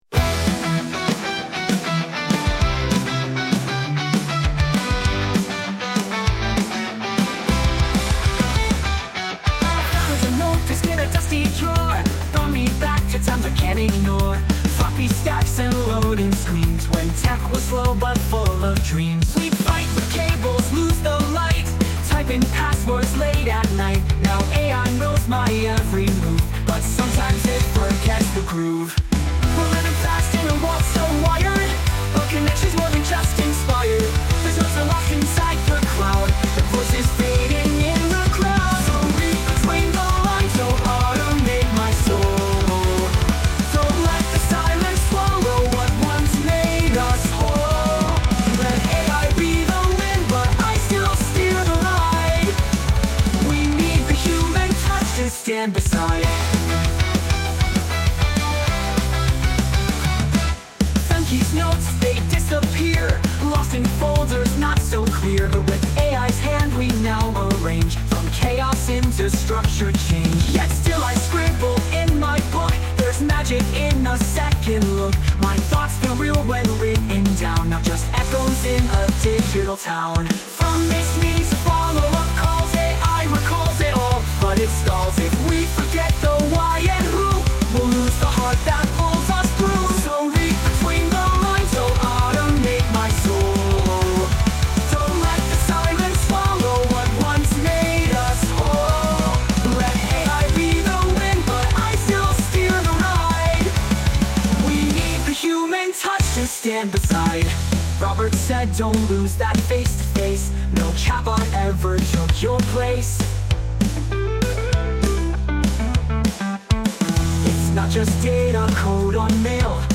Dit lied is volledig met AI gegenereerd. De teksten zijn afkomstig van de interviews van aflevering 4.